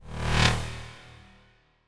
14. WHO WHAT FX.wav